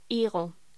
Locución: Higo